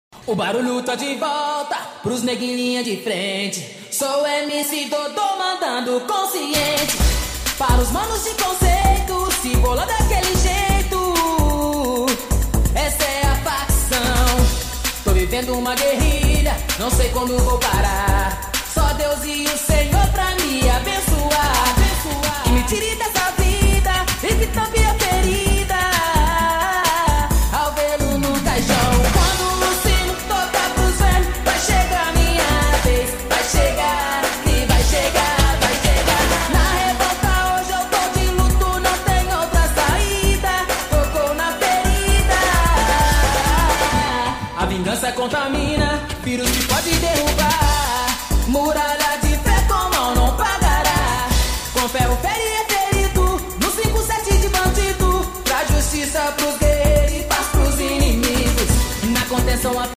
8D ÁUDIO 💀 Tocou Na Sound Effects Free Download